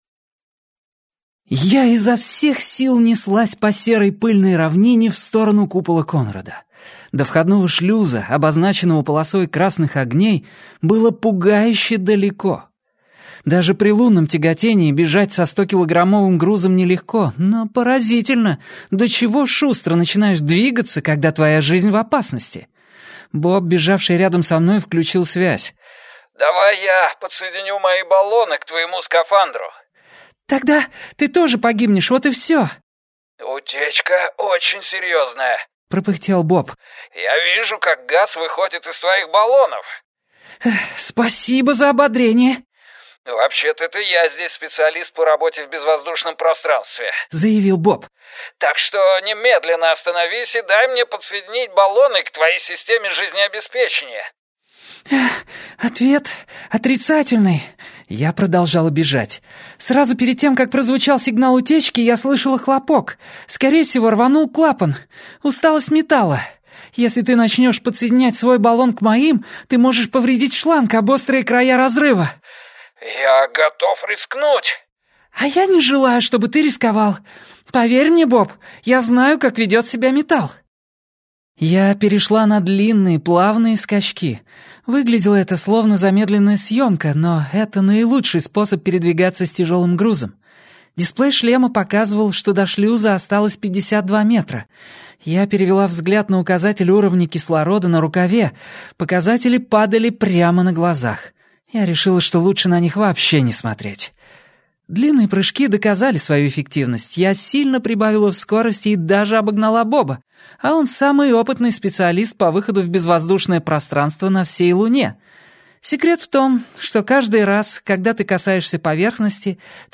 Аудиокнига Артемида - купить, скачать и слушать онлайн | КнигоПоиск